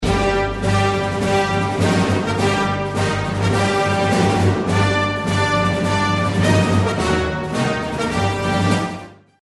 powerful